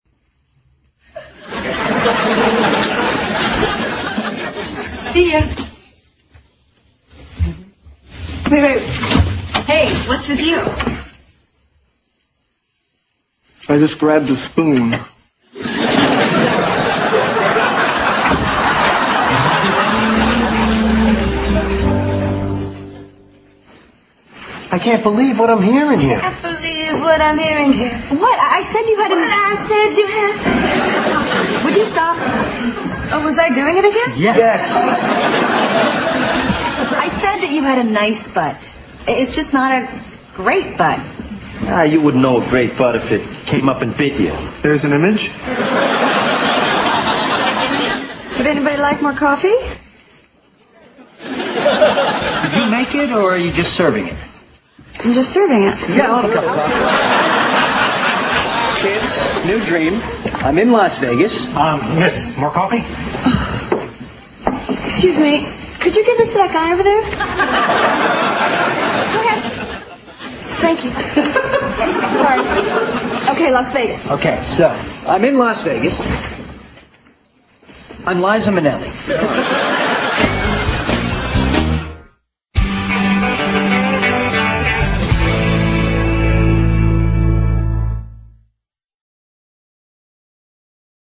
在线英语听力室老友记精校版第1季 第12期:莫妮卡的新室友(12)的听力文件下载, 《老友记精校版》是美国乃至全世界最受欢迎的情景喜剧，一共拍摄了10季，以其幽默的对白和与现实生活的贴近吸引了无数的观众，精校版栏目搭配高音质音频与同步双语字幕，是练习提升英语听力水平，积累英语知识的好帮手。